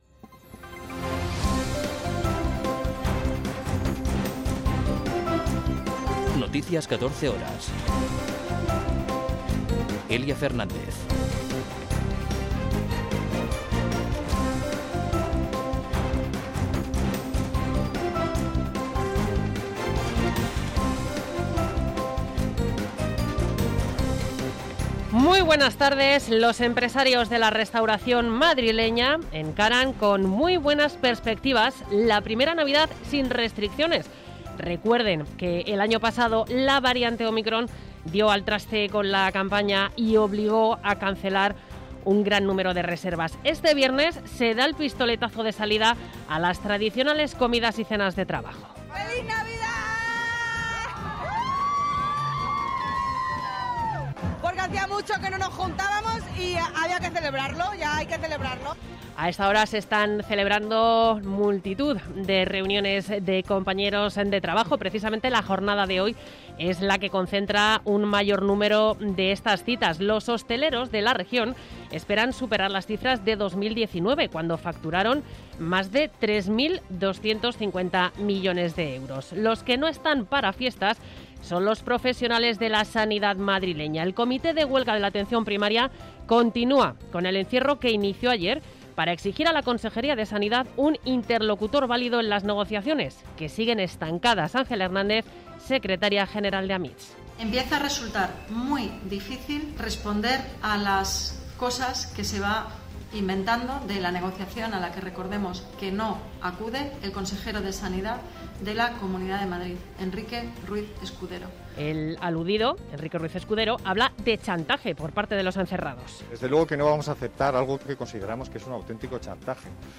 Noticias 14 horas 16.12.2022